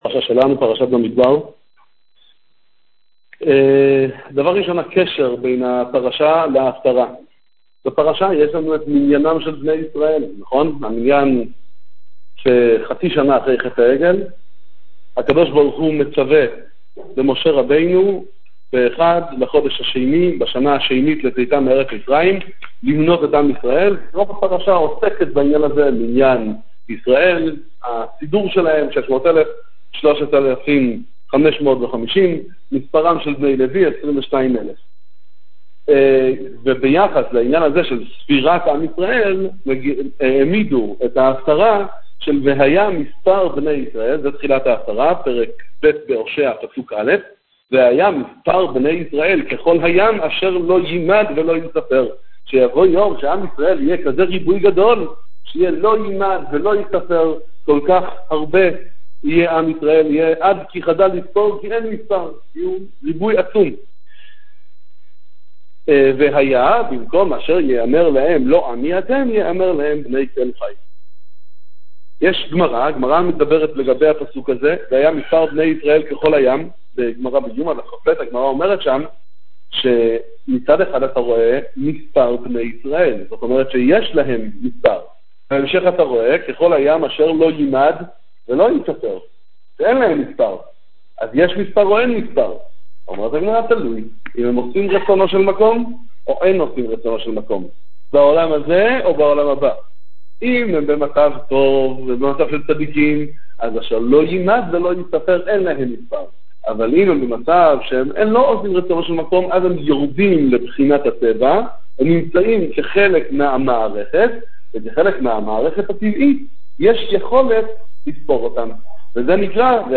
שיעור תורה בהפטרת פרשת במדבר, לימוד נביאים וכתובים עם פירוש המלבי"ם